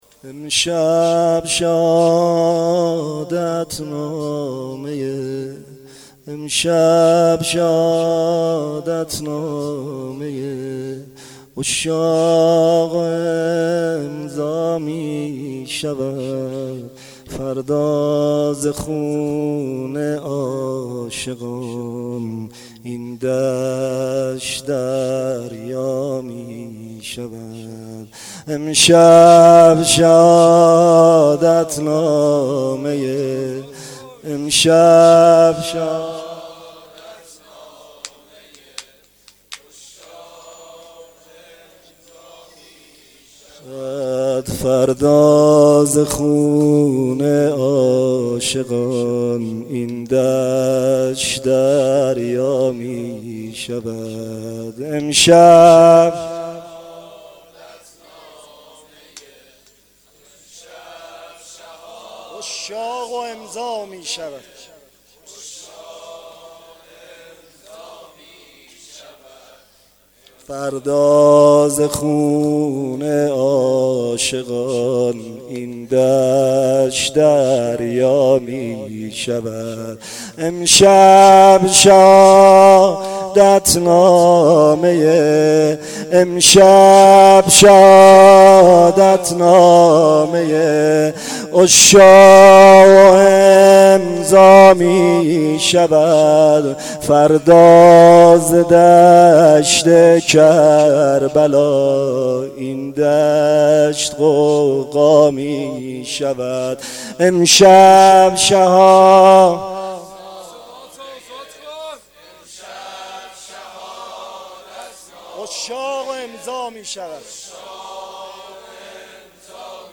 نوحه شب عاشورا محرم1393